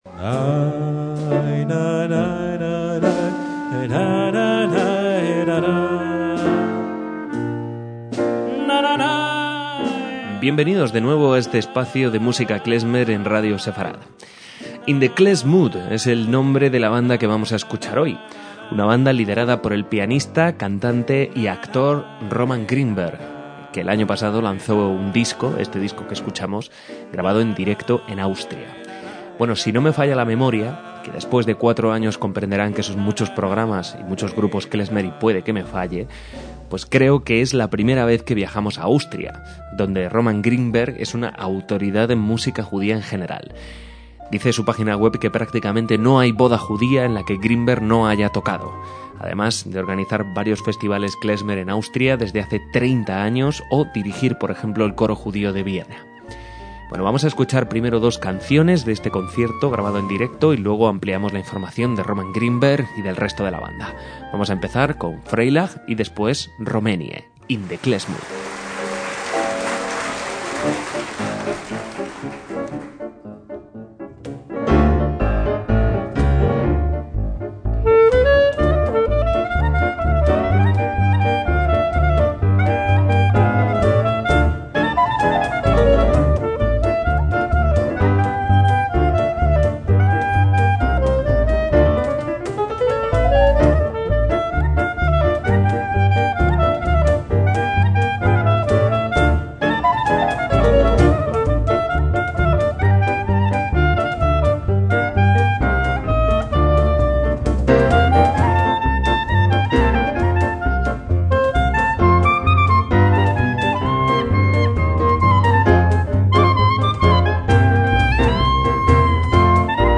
MÚSICA KLEZMER
contrabajo
clarinete